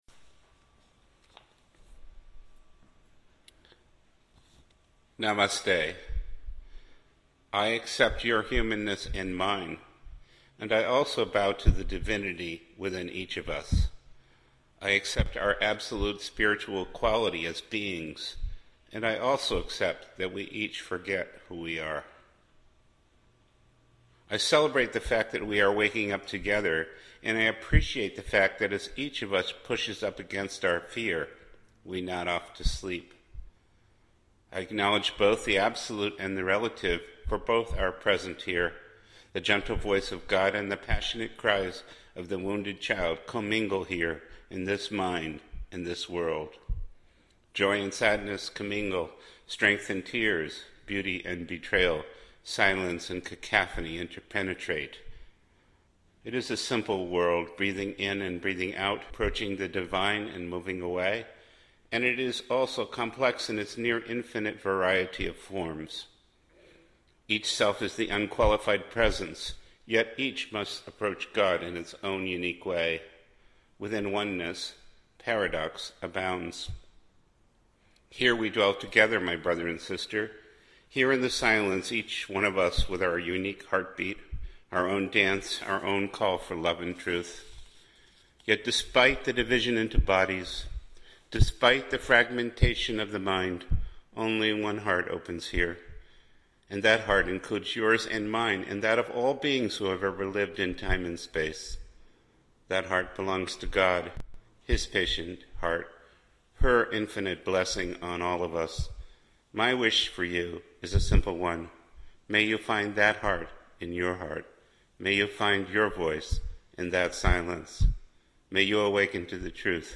reading from his Christ Mind titles.